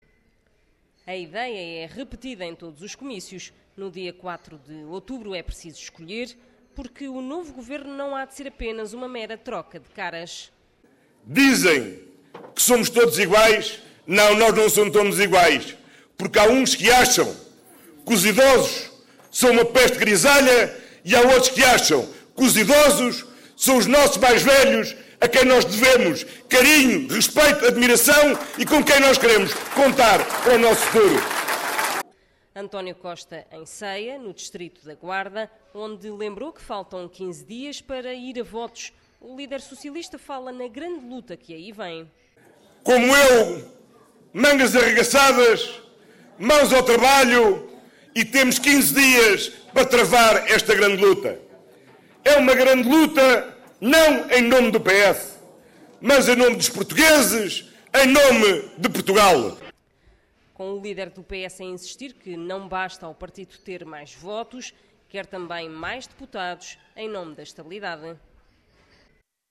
Costa sublinhou, perante centenas de apoiantes, que o cabeça de lista da coligação PSD/CDS no distrito, Carlos Peixoto, chamou aos idosos no passado "peste grisalha", num texto de opinião publicado no jornal i em 2013.
Reportagem